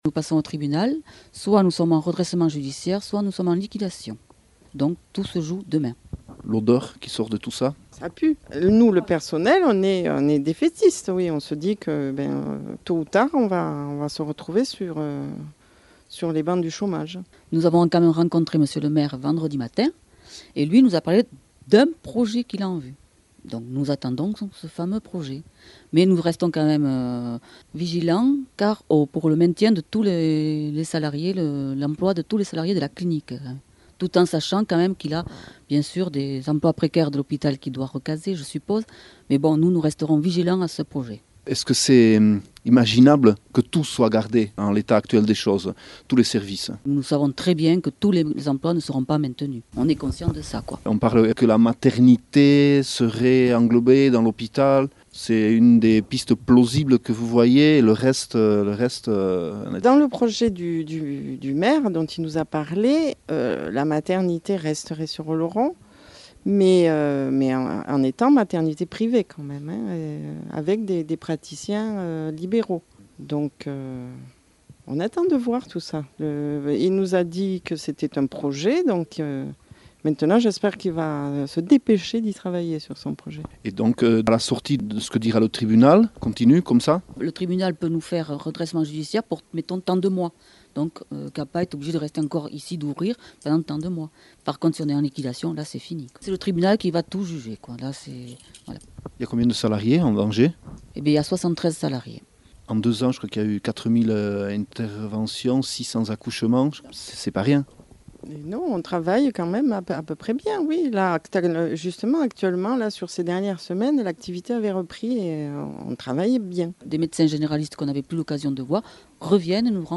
Entzün Poliklinikako Enpresa komiteko langile bat :